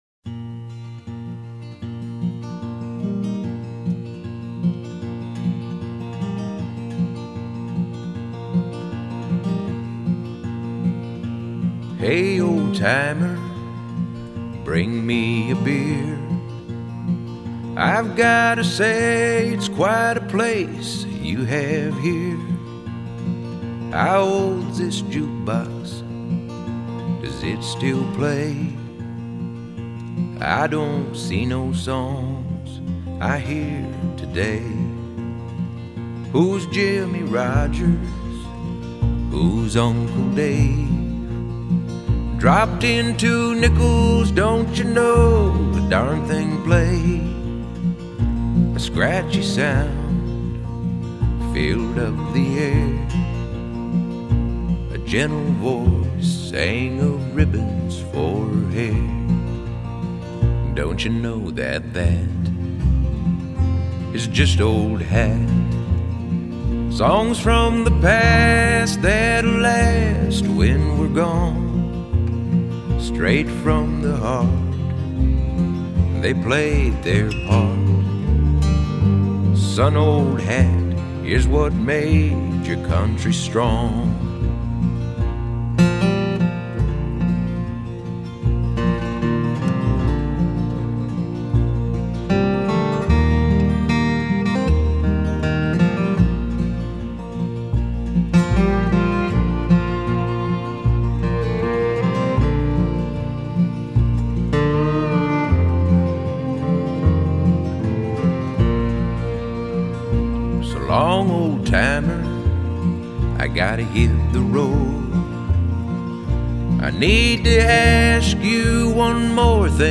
It was a folky melody set to a lyric that lamented what was already happening to the music industry here.
bass
fiddle
guitar. We set down a track that a banjo could always be added to later.